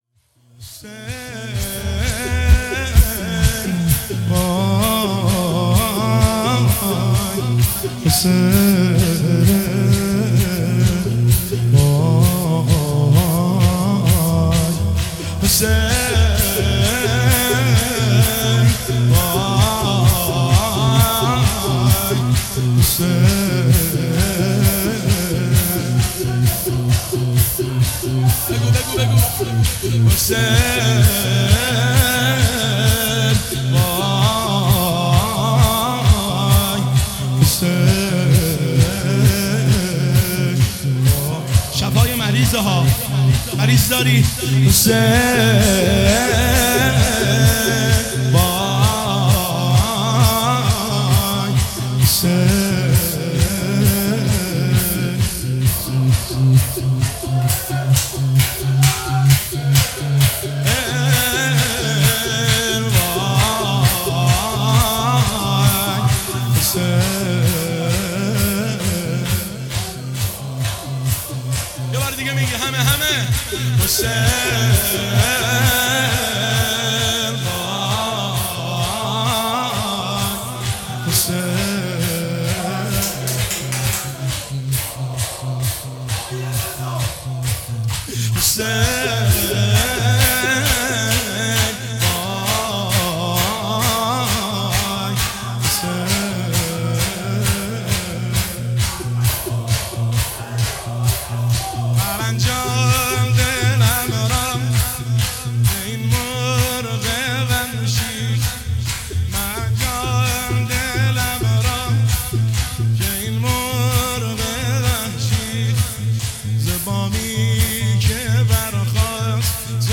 شور ایام فاطمیه دوم 1404
هیئت محبان ثارالله جنت آباد تهران